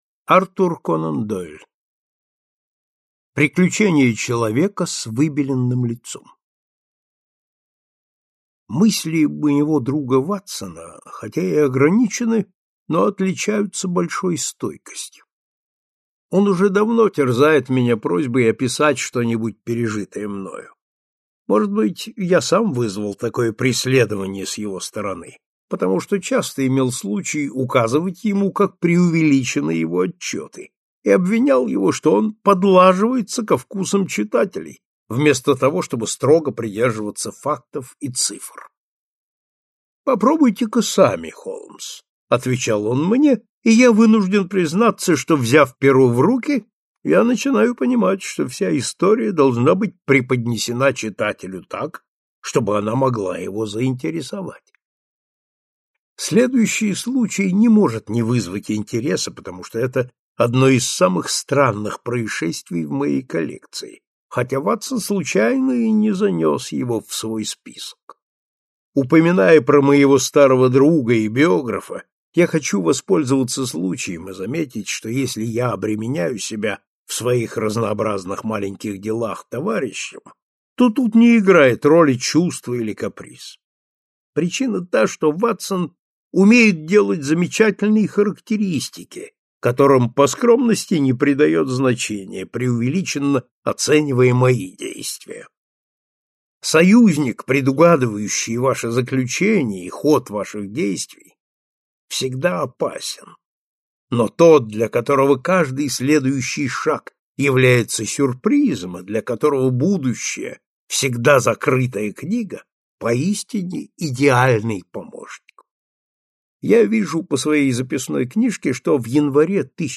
Аудиокнига Приключение человека с выбеленным лицом | Библиотека аудиокниг